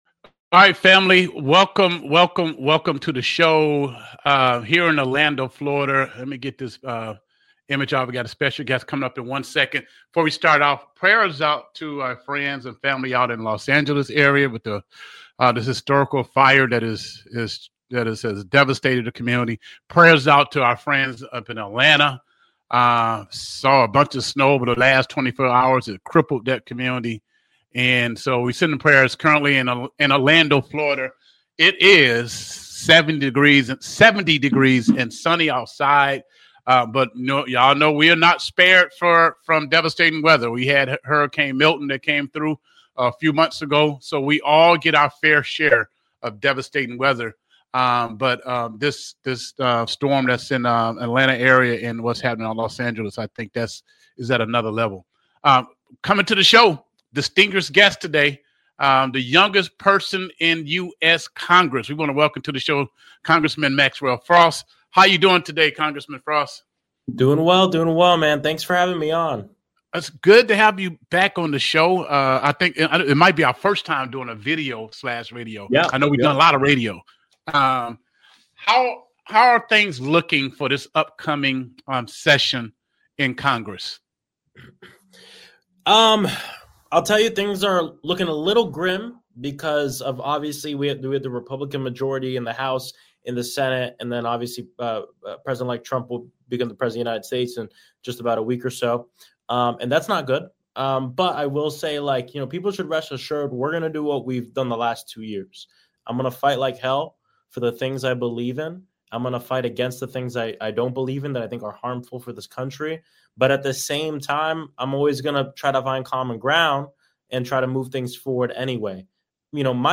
Live with US Congressman Maxwell Frost